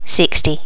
Update all number sounds so they are more natural and remove all clicks.